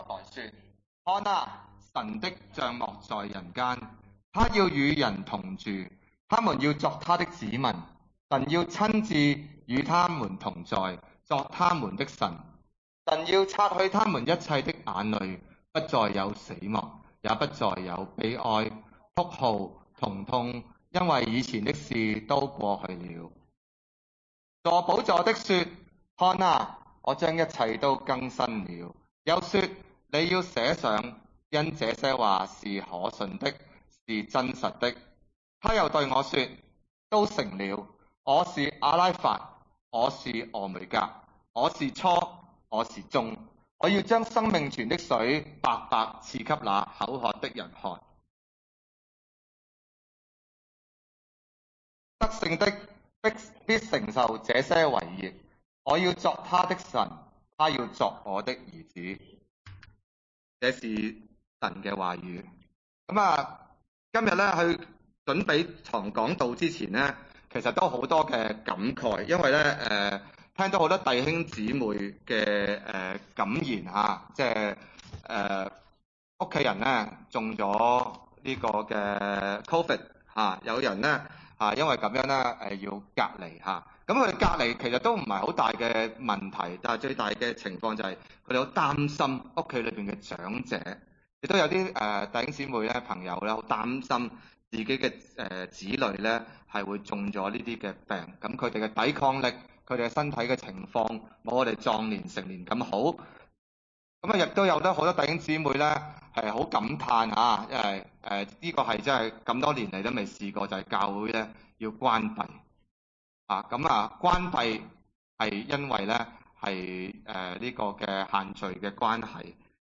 啟二十一1:1-7 崇拜類別: 主日午堂崇拜 1我又看見一個新天新地；因為先前的天地已經過去了，海也不再有了。